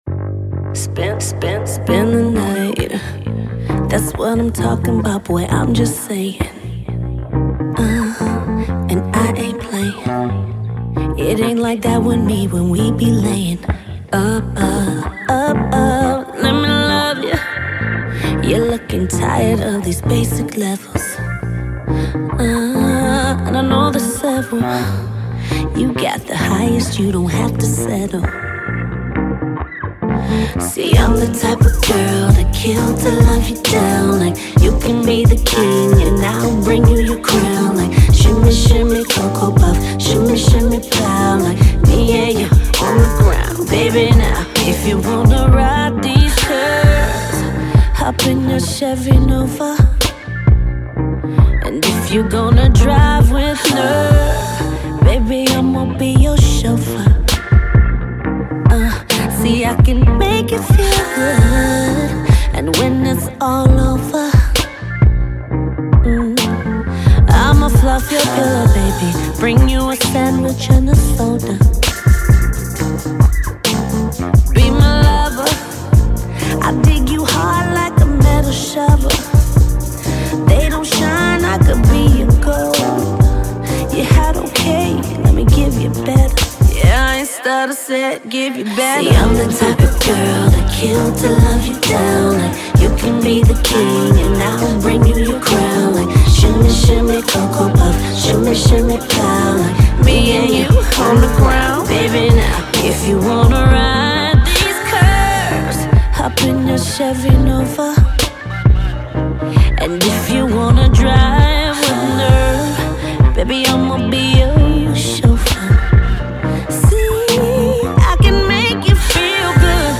church organ
acoustic guitar